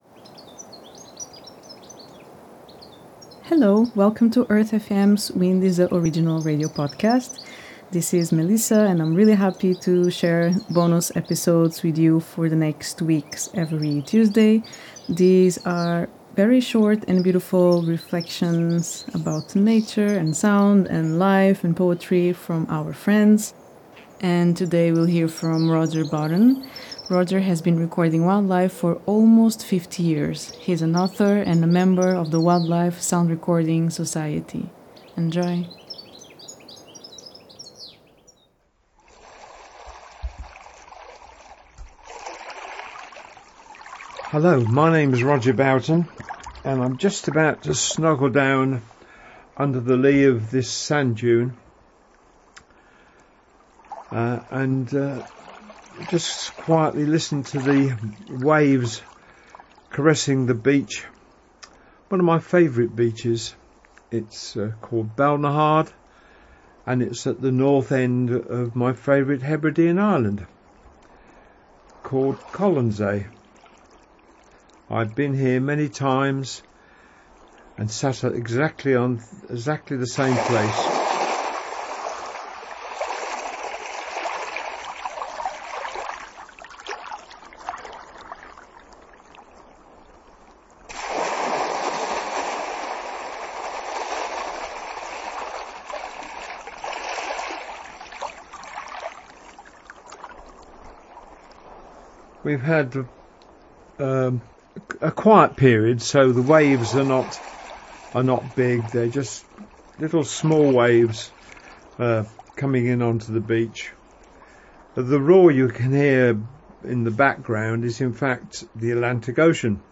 Gentle Waves